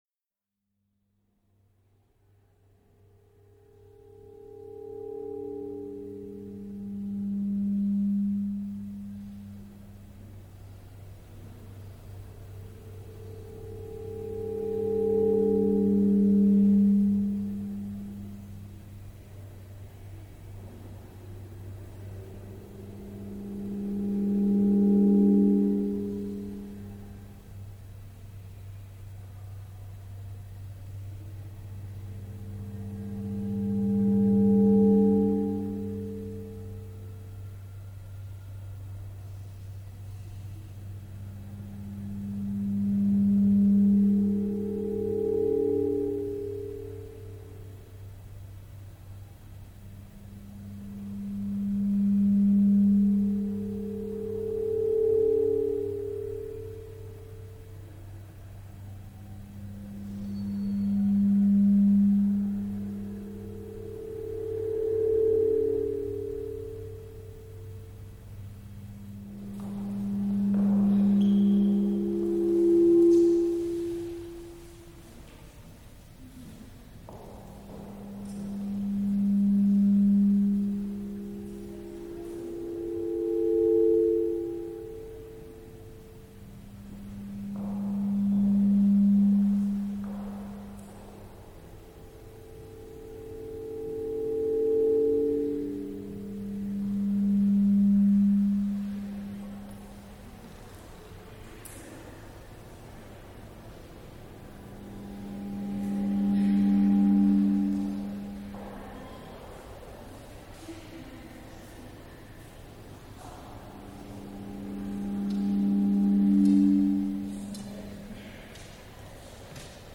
Each installation amplifies the sound of a gallery in real time, introducing low-volume feedback, pitches delineated by the architectural characteristics of the space and modulated by the transient nature of people passing through.
The technology in each room consists of a system of microphone, noise gate, amplifier and speakers in the room, arranged in such a way that when the microphone and loudspeaker begin to feed back the amplitude of the sound causes the noise gate to cut off the signal. The feedback notes resonate through the space accentuated by the reverberation time of the space.
Available feedback pitches are a function of the resonant frequency of the space.
A fragment of time within the installation, recorded on 7 November 2003